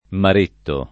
[ mar % tto ]